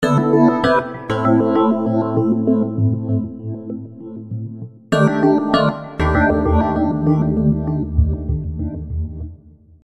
反转钢琴
标签： 98 bpm Trap Loops Piano Loops 1.65 MB wav Key : D
声道立体声